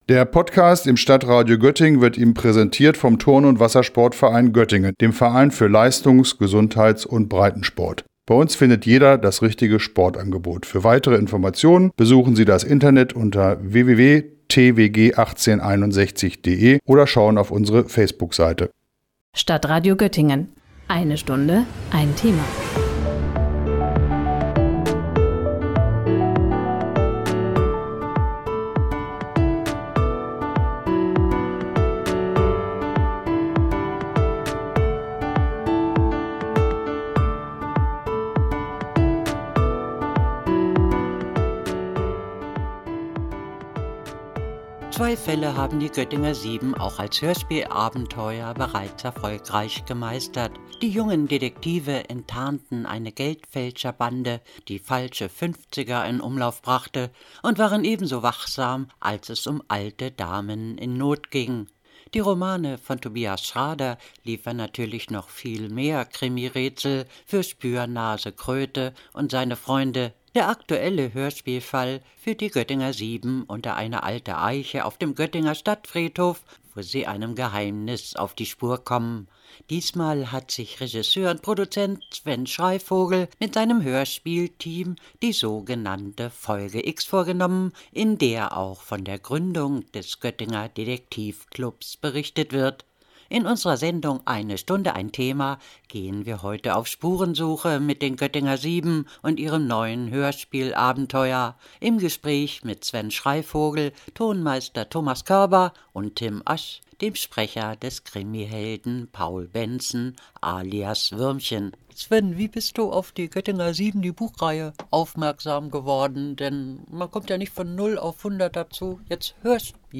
„Die Göttinger Sieben“- Hörspielabenteuer für junge Krimifans